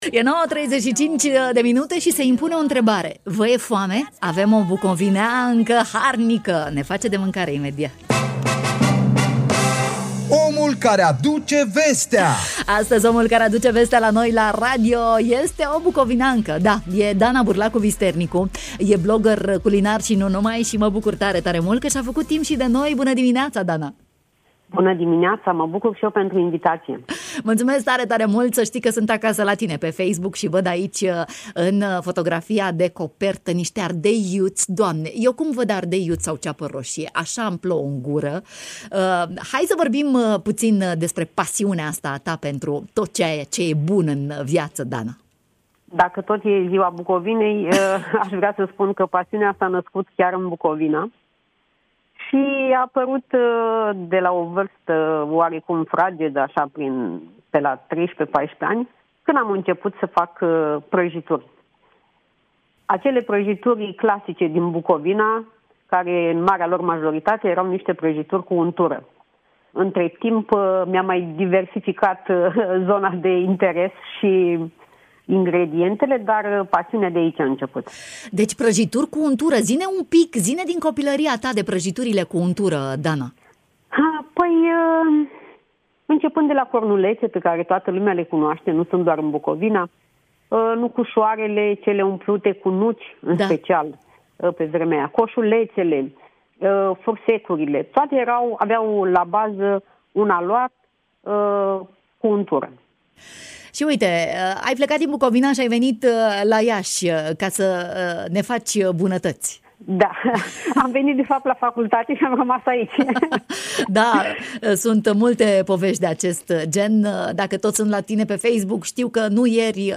Despre beneficiile cătinei în matinalul de la Radio România Iași: